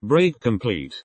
break-complete.mp3